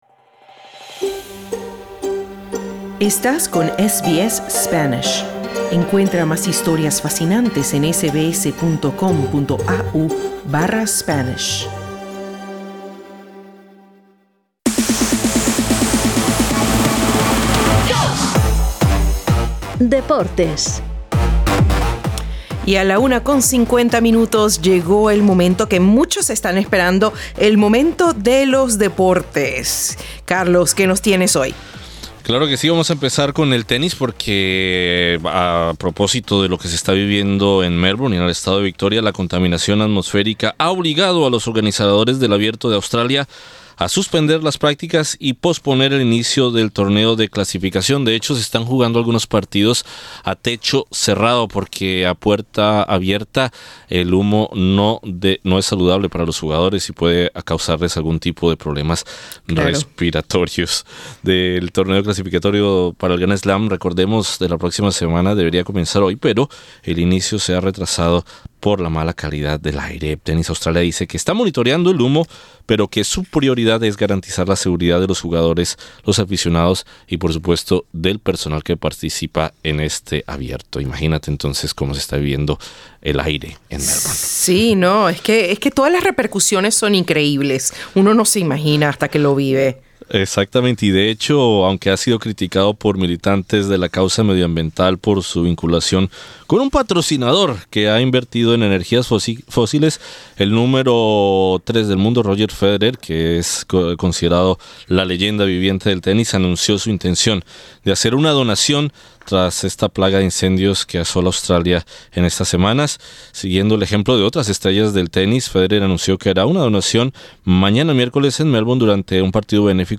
La contaminación atmosférica en Melbourne ha obligado a los organizadores del Abierto de Australia a suspender las prácticas y a posponer el inicio del torneo de clasificación. Escucha esta y otras noticias deportivas del día.